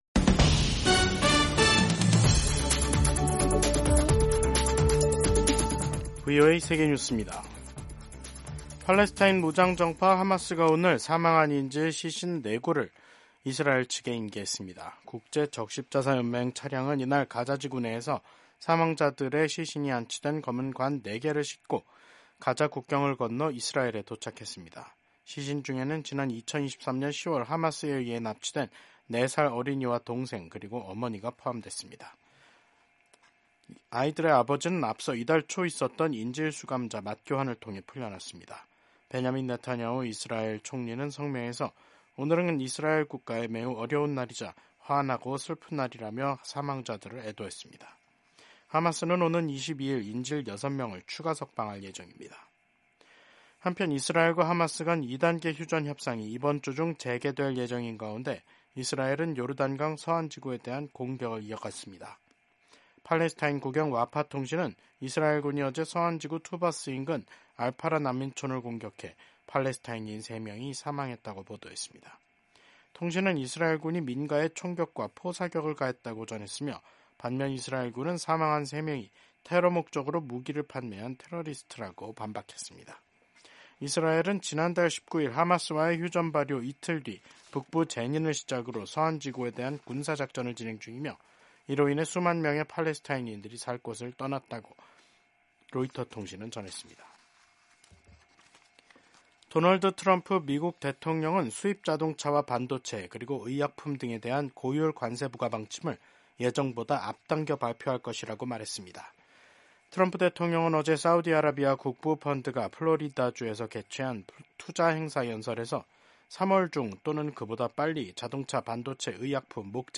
세계 뉴스와 함께 미국의 모든 것을 소개하는 '생방송 여기는 워싱턴입니다', 2025년 2월 20일 저녁 방송입니다. 도널드 트럼프 미국 대통령과 볼로디미르 젤렌스키 우크라이나 대통령이 설전을 주고받으며 관계가 급속히 악화하고 있습니다. 23일 독일 총선을 앞두고 올라프 숄츠 총리와 프리드리히 메르츠 기독민주당(CDU) 대표가 19일 마지막 TV 토론을 벌였습니다.